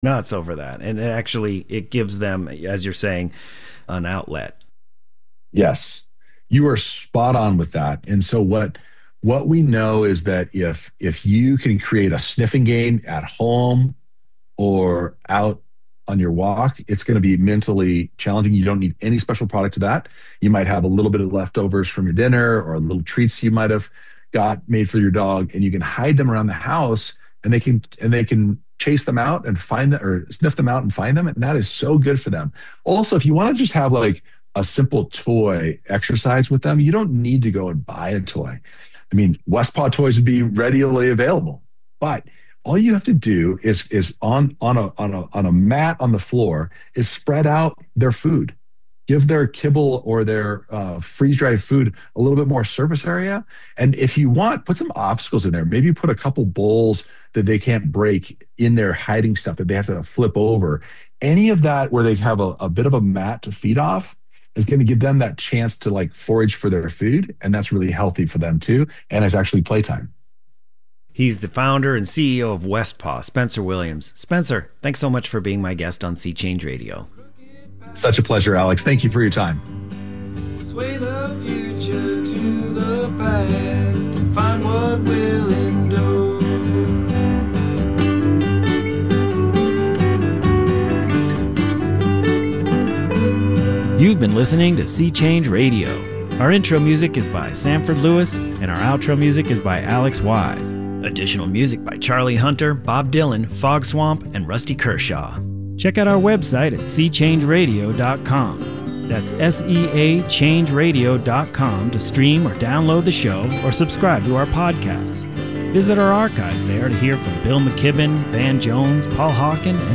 11:30am to 12:00pm A radio show about visual art.